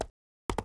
pony_run.wav